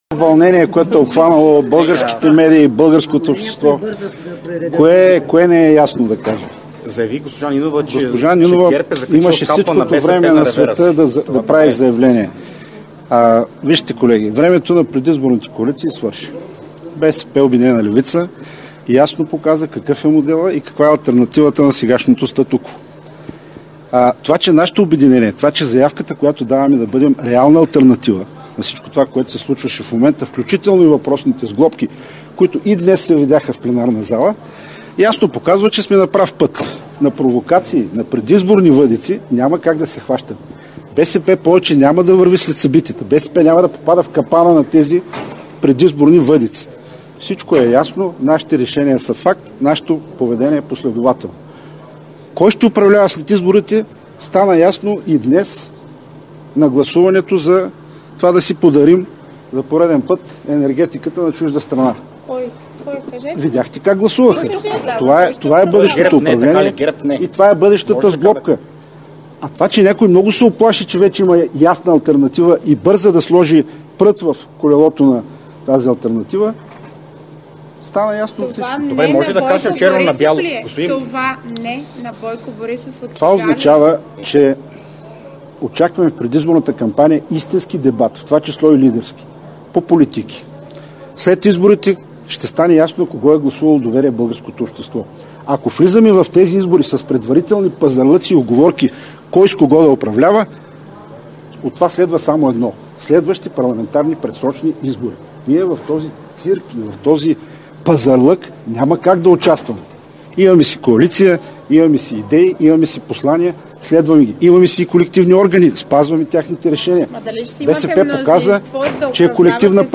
12.05 - Брифинг на председателя на ПГ на ИТН Тошко Йорданов. - директно от мястото на събитието (Народното събрание)
Директно от мястото на събитието